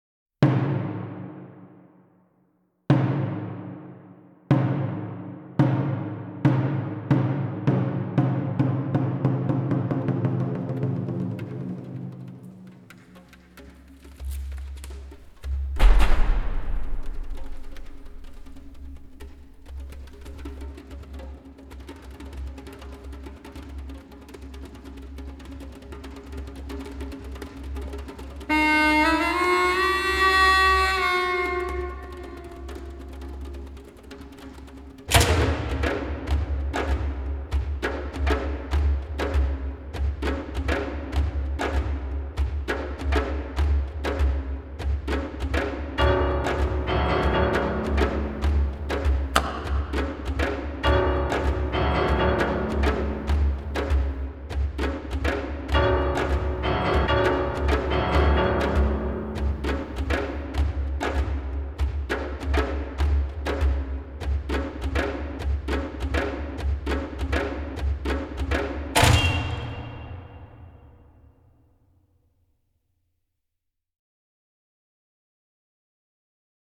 Djembe percussion group